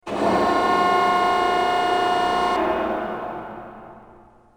repair6.wav